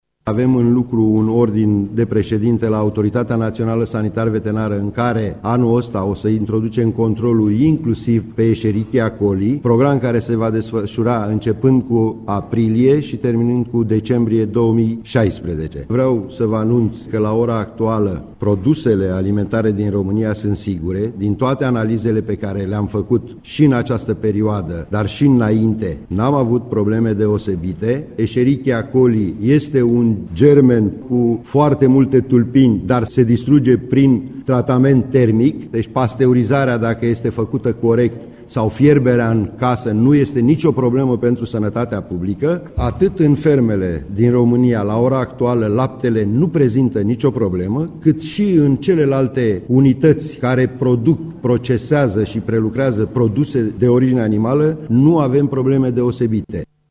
De asemenea, Andrei Butaru a spus ca depozitarea este de scurtă durată, iar probleme apar acolo unde sunt intermediari: